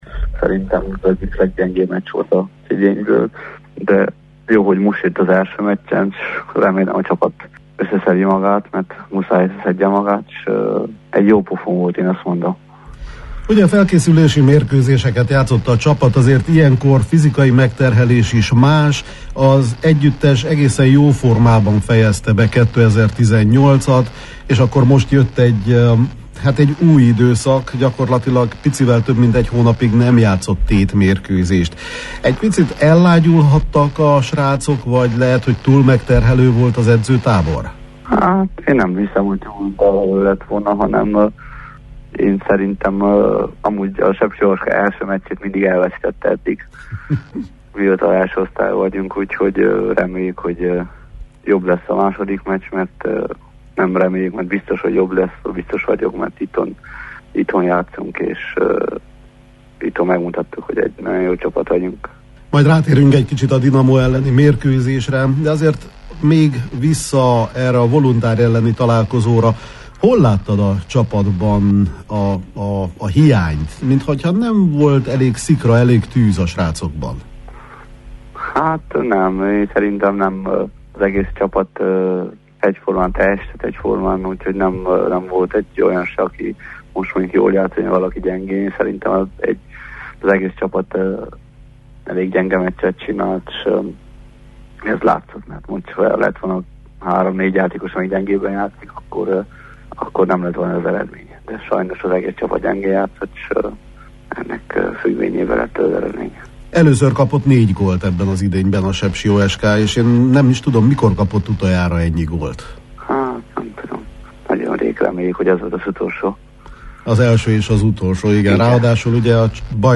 Az OSK átigazolásairól, a többi csapatról, a Dinamo elleni összecsapásról és a felsőházi esélyekről beszélgettünk a Kispadban: